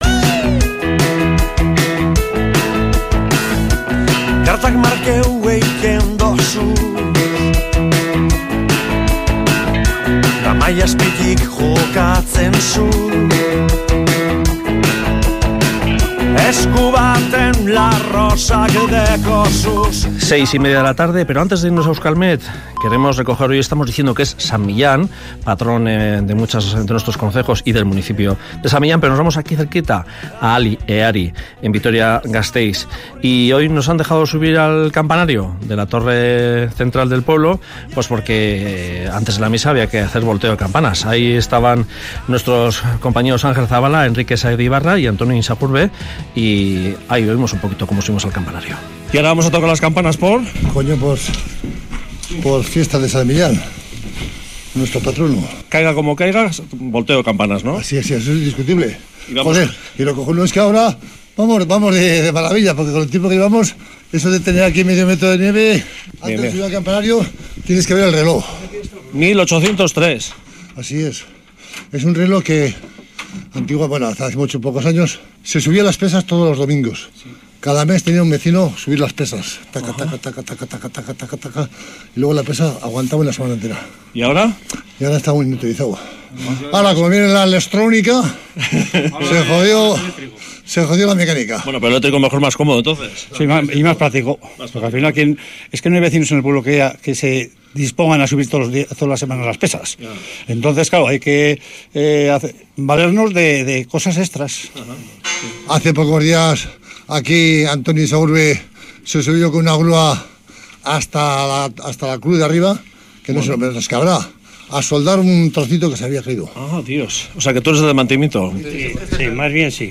Volteo de campanas por San Millán en Ali-Ehari